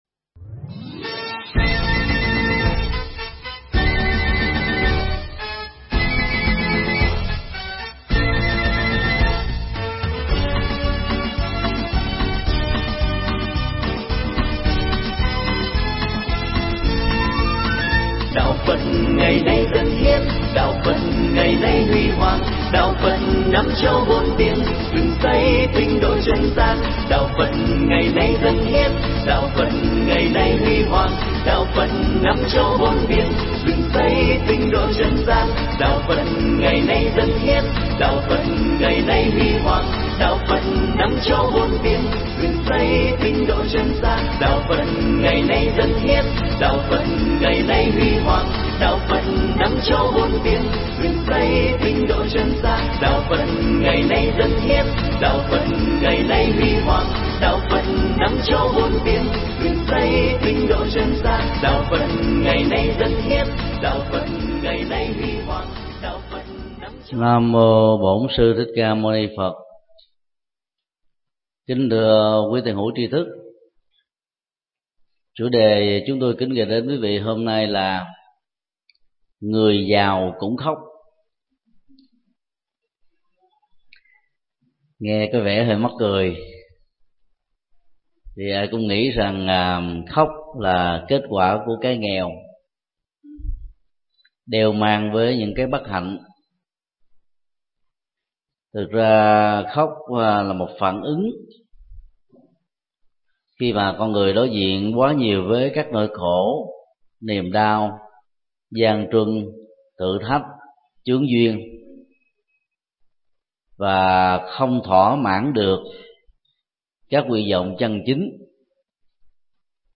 Tải mp3 Pháp thoại Người giàu cũng khóc do thượng toạ Thích Nhật Từ giảng tại chùa Giác Ngộ, ngày 18 tháng 06 năm 2012